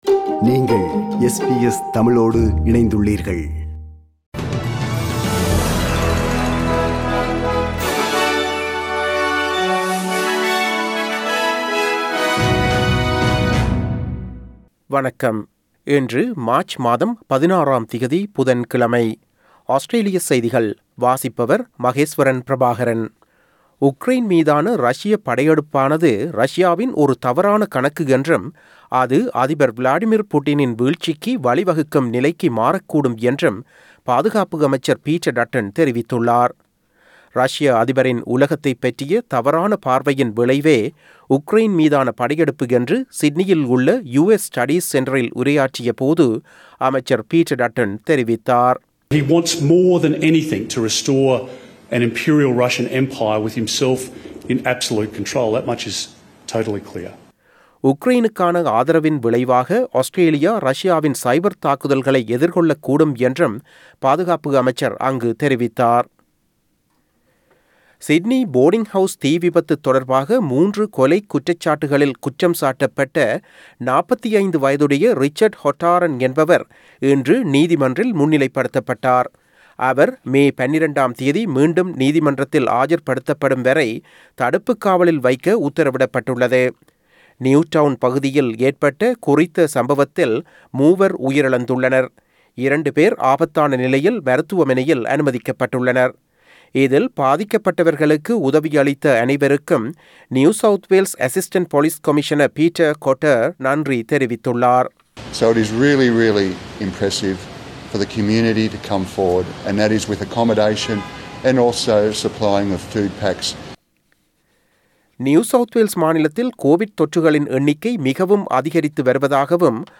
Australian news bulletin for Wednesday 16 March 2022.